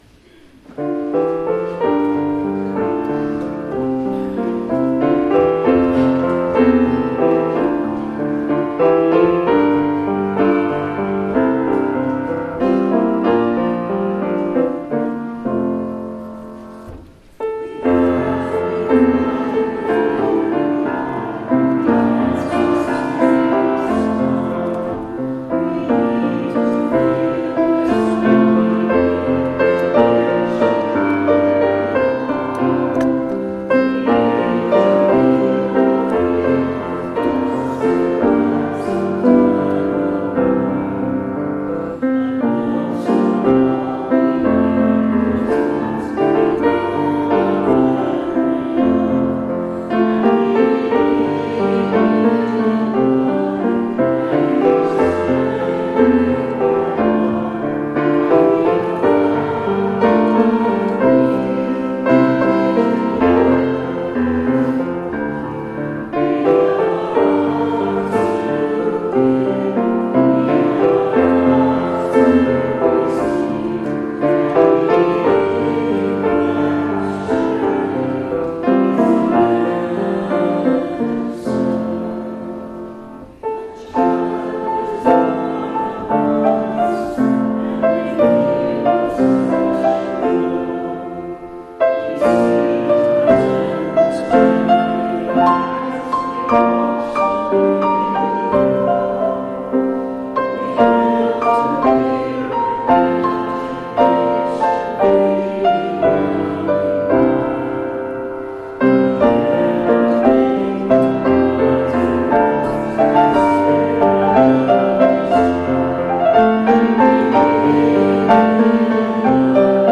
[Fuusm-l] HYMN May 17 (Congregation)
Hymn No.354  - We Laugh. We Cry